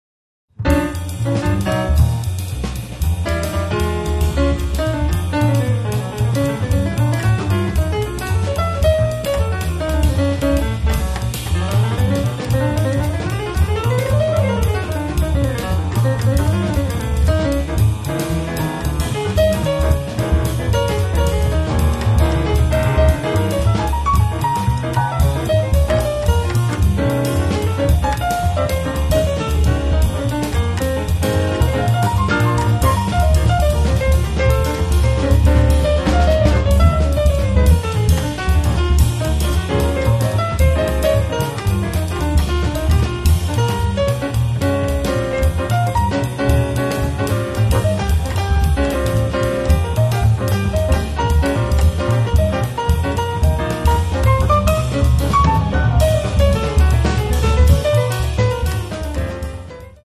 tromba, flicorno
sax soprano
pianoforte
contrabbasso
batteria
bandoneon